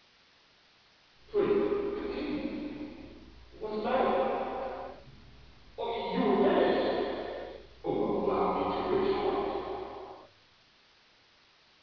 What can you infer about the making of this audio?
We synthetically produce a reverbed signal and aim to estimate the original form from this observation. Observation is modified to have signal to noise ratio (SNR) '30'. Noise is added after reverb effects.